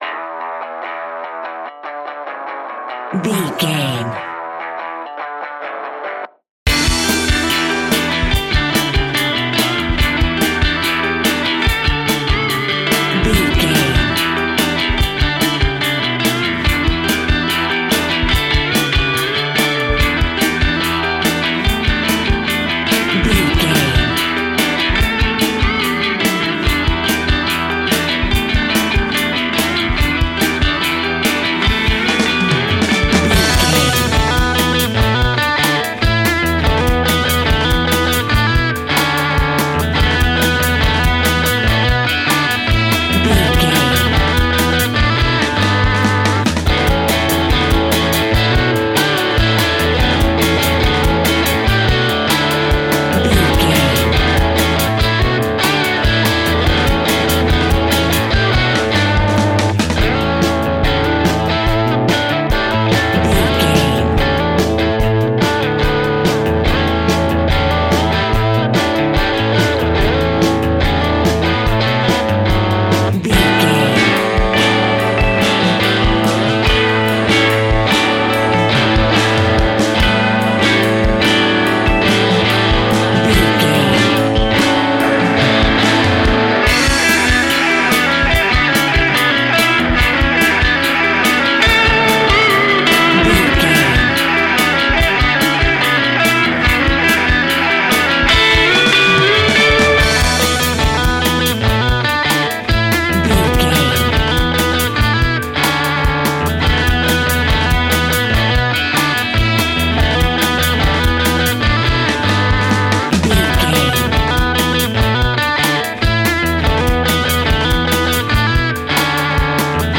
Ionian/Major
cool
uplifting
bass guitar
drums
cheerful/happy